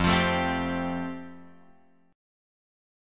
F Major Add 9th